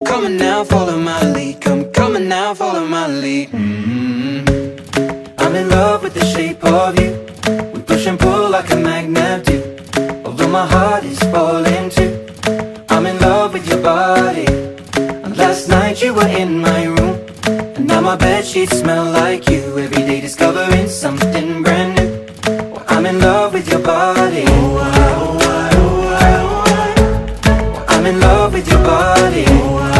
آهنگ غمگین زنگ خور گوشی